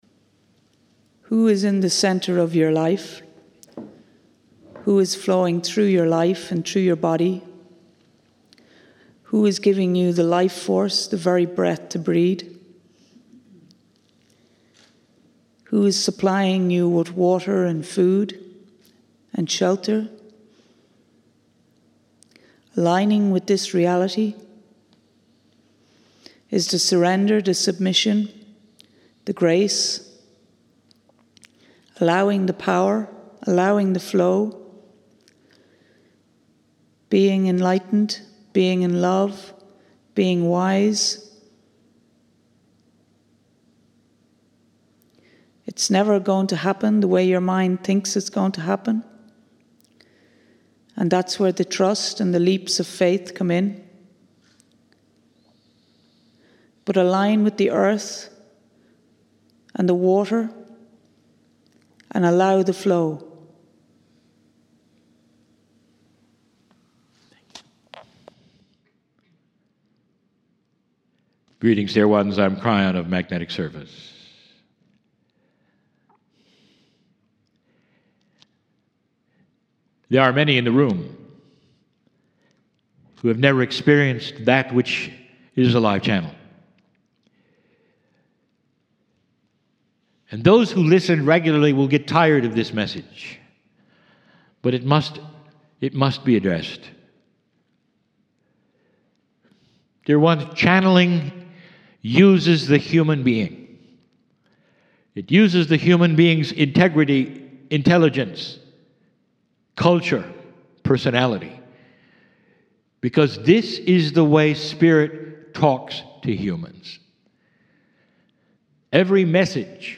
"Mini Channelling"